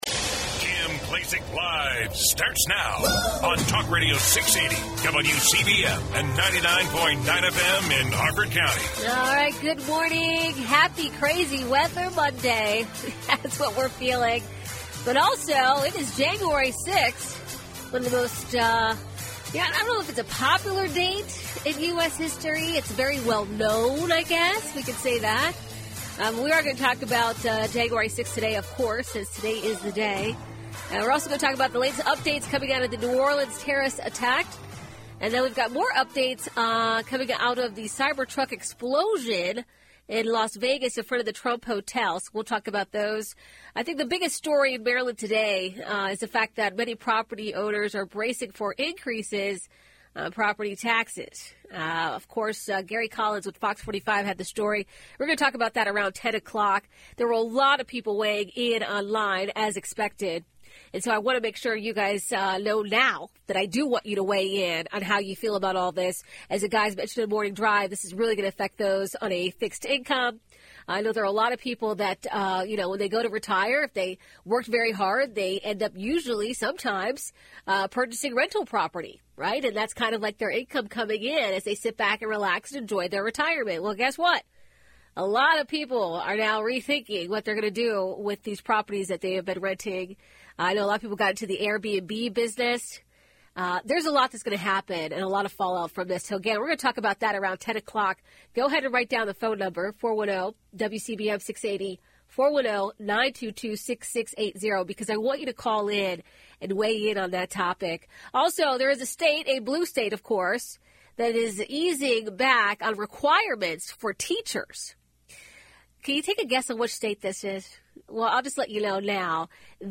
Kim Klacik is a dynamic voice who isn’t afraid to speak her mind.
Don’t miss your chance to hear from the one and only Kim Klacik live on WCBM weekdays from 9am to noon.